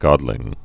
(gŏdlĭng)